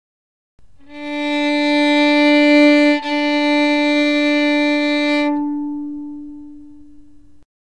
Violin_open_string.ogg